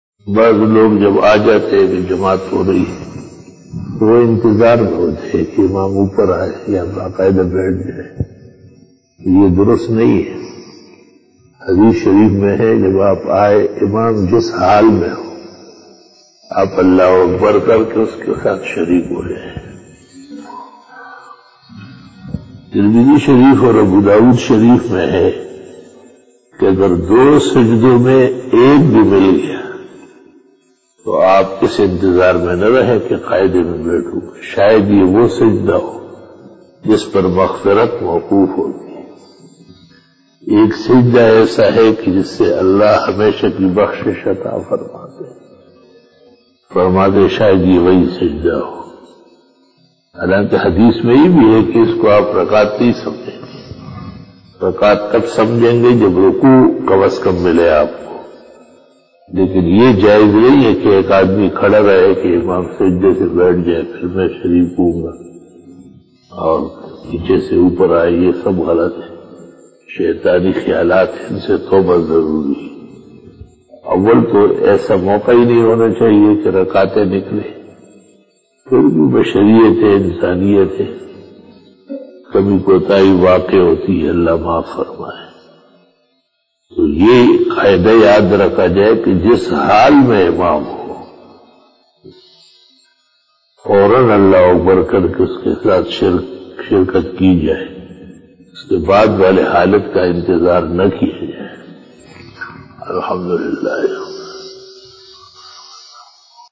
After Namaz Bayan
بیان بعد نماز عصر